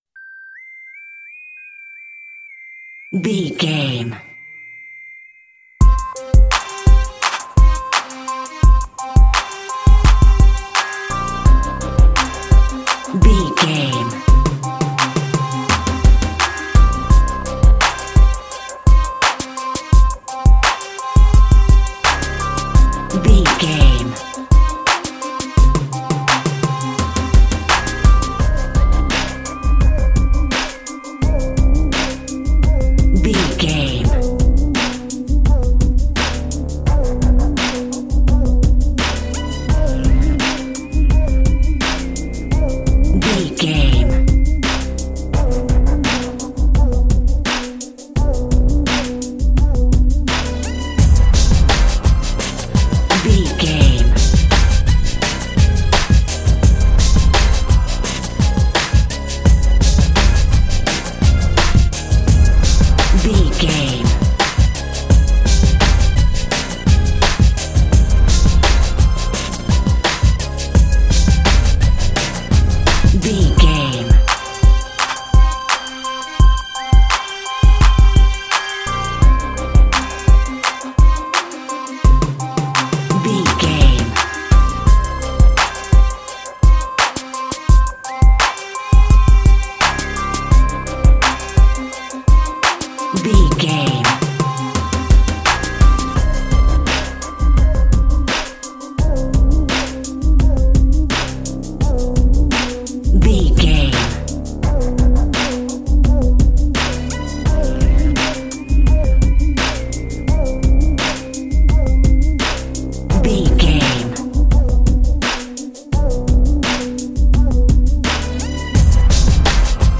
Aeolian/Minor
drum machine
synthesiser
hip hop
soul
Funk
neo soul
acid jazz
r&b
energetic
cheerful
bouncy
funky
driving